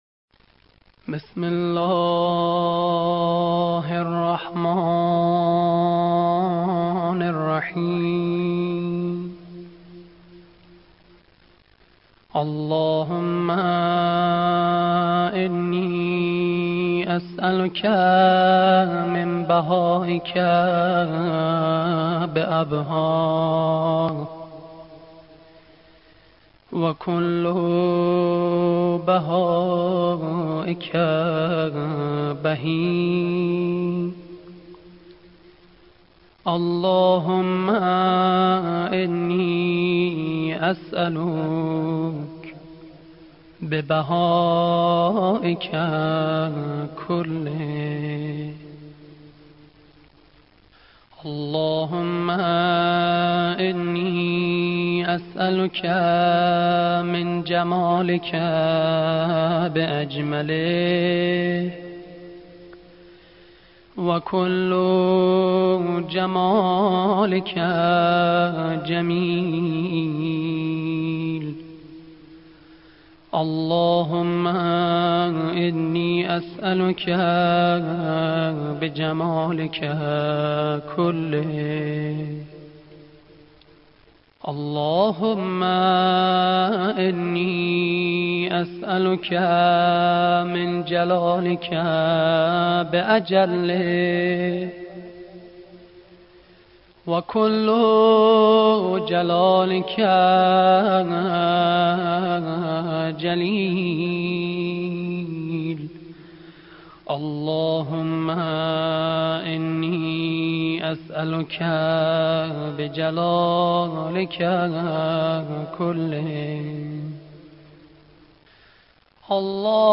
قرائت دعای سحر